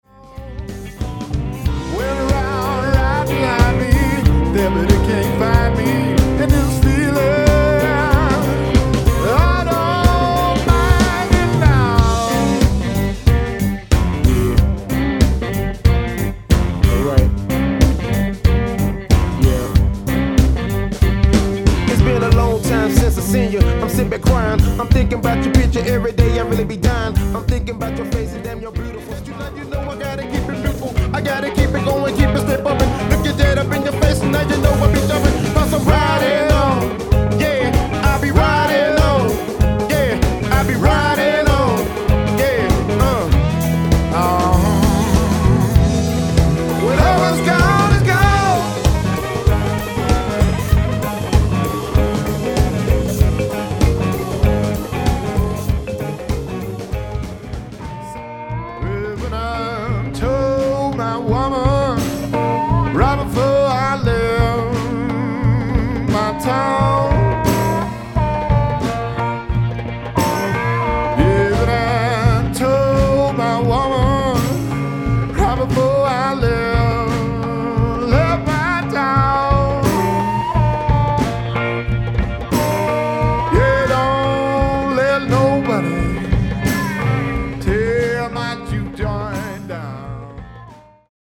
Genre/Style: Blues